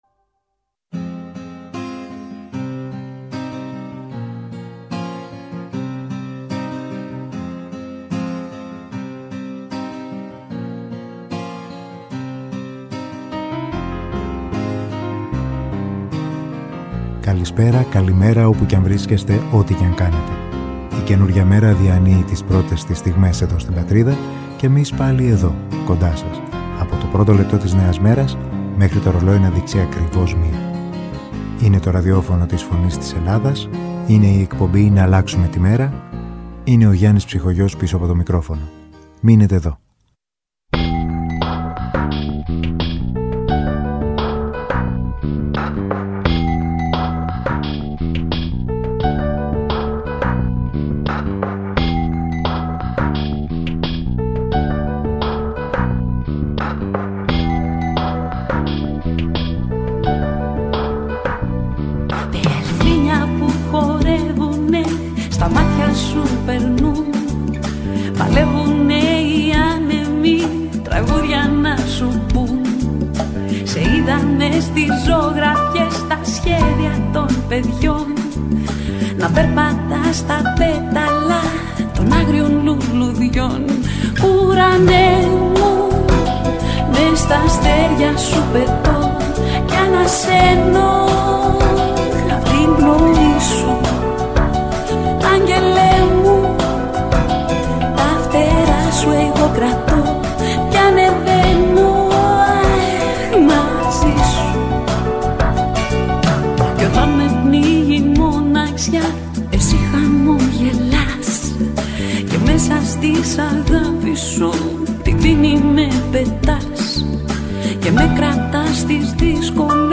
Στην αρχή μιας νέας μέρας με μουσικές
Μουσική